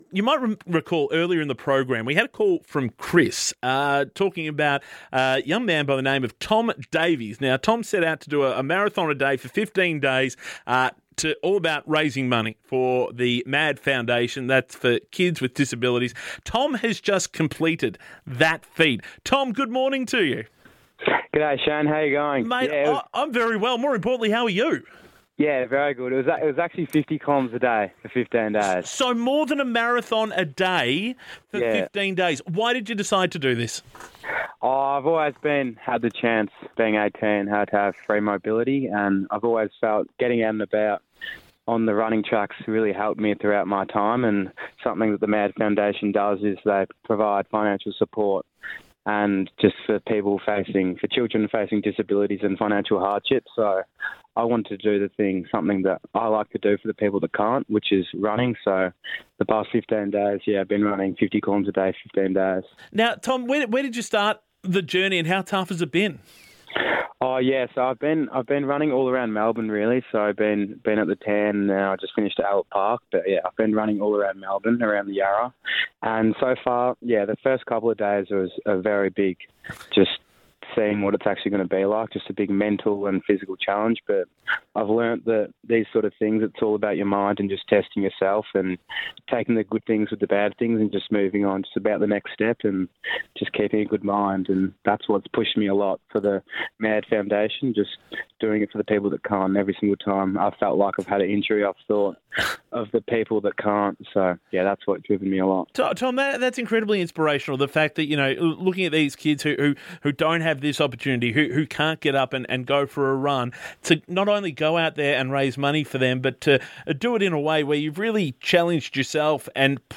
radio interview on 3AW.